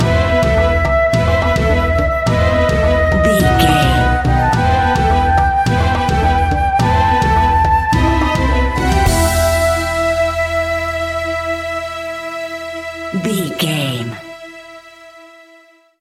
Aeolian/Minor
percussion